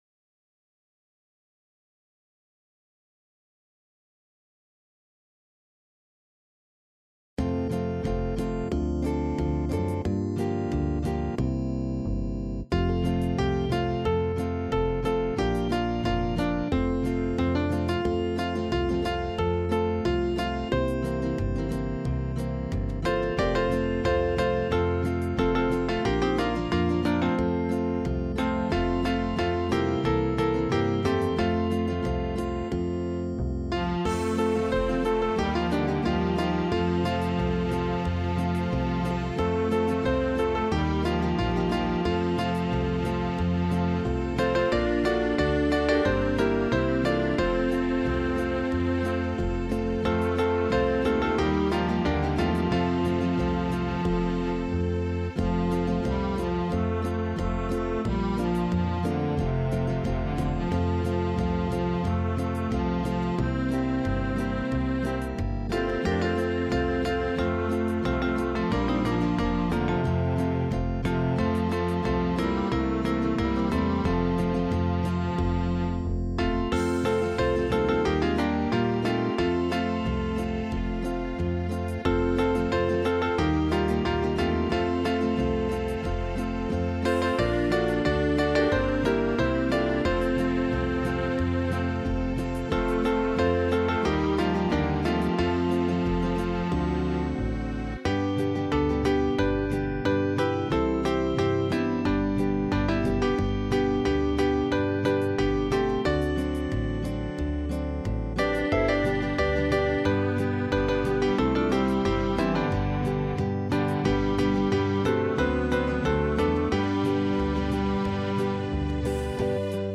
8-beat intro.
Play: Chorus, Verse, Chorus, Verse, Chorus Key Change.